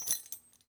foley_keys_belt_metal_jingle_05.wav